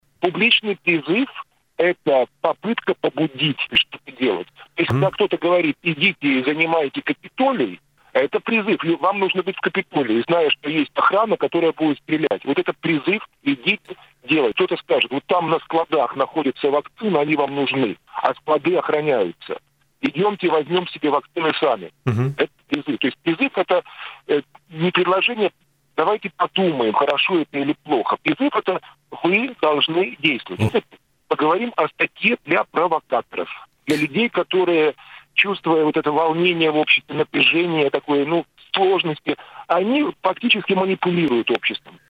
Инициатива Сейма о наказании за призыв к нарушению законов не ущемляет свободы граждан на высказывание своей точки зрения. Об этом в эфире радио Baltkom рассказал доктор юридических наук и депутат Сейма Андрей Юдин.